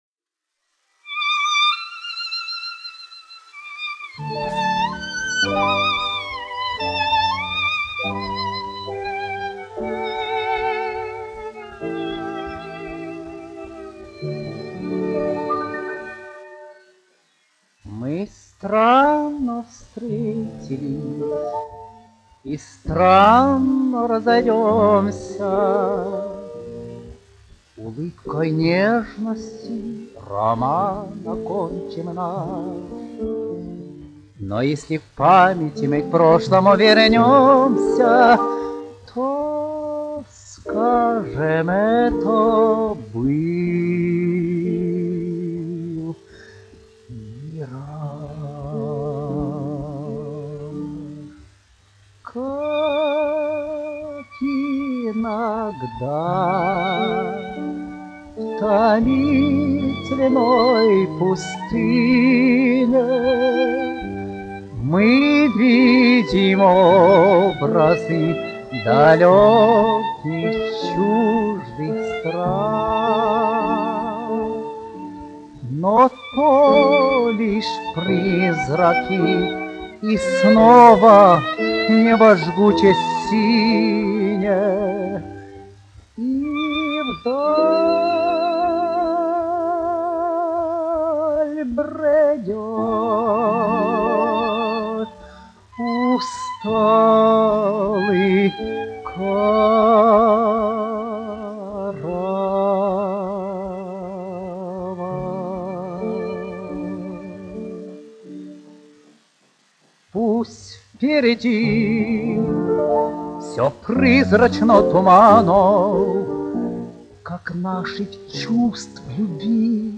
1 skpl. : analogs, 78 apgr/min, mono ; 25 cm
Romances (mūzika)
Krievu dziesmas
Skaņuplate